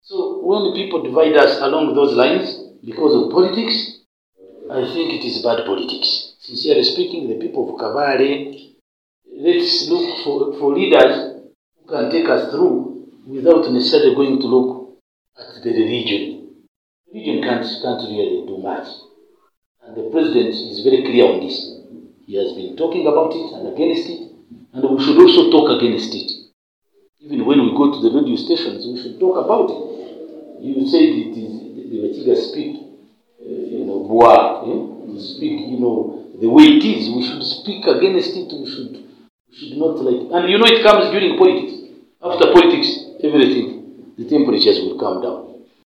Kabale-In a significant leadership transition, Michael Muramira Kyakashari has officially assumed office as the new Deputy Resident District Commissioner (RDC) of Kabale District, replacing Christopher Aine in a ceremony held at the Deputy RDC’s office on Makanga Hill, Kabale Municipality.